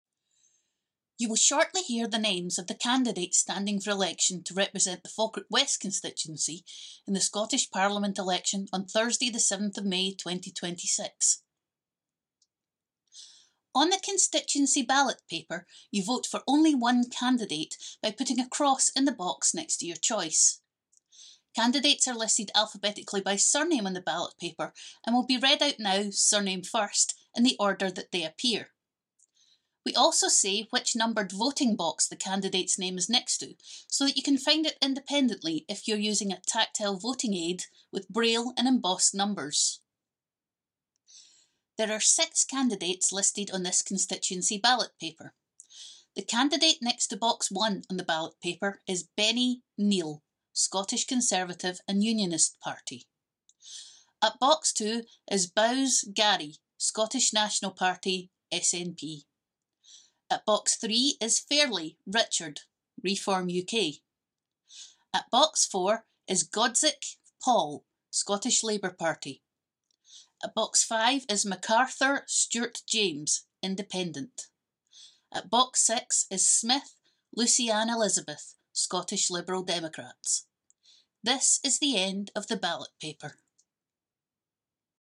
• Audio description of the ballot paper